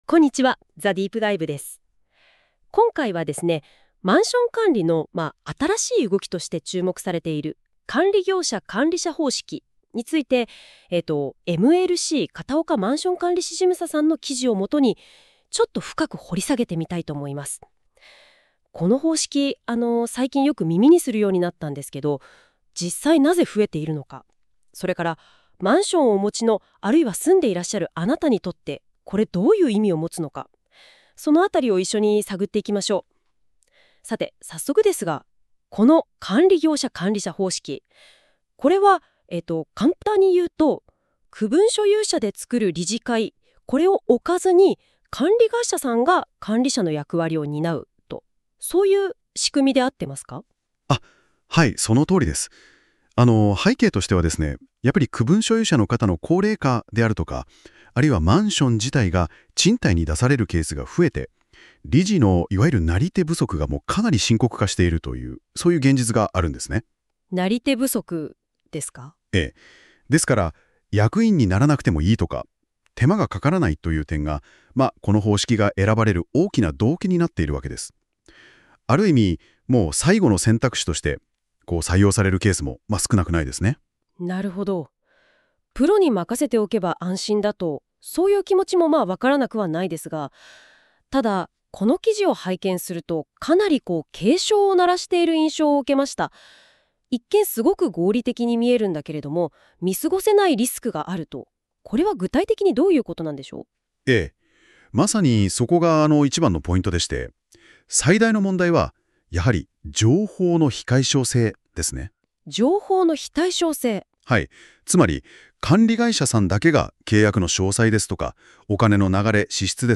🎧 音声解説（約8分）